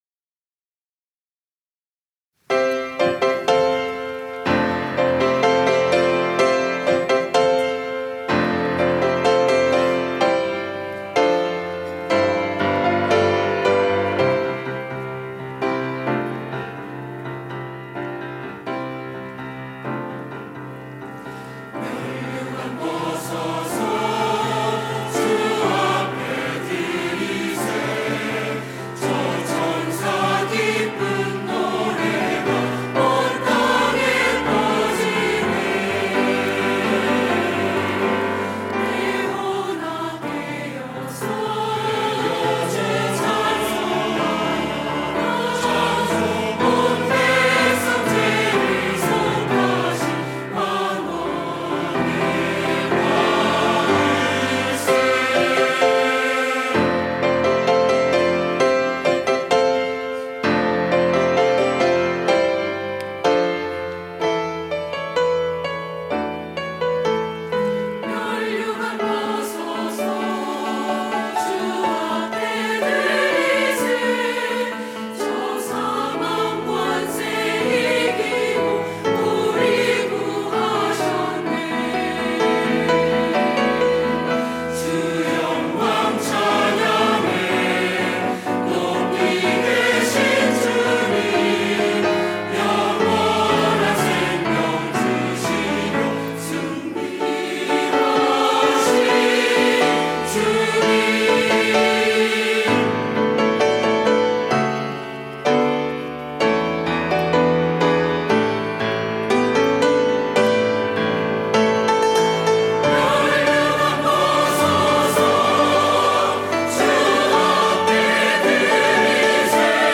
시온(주일1부) - 면류관 벗어서
찬양대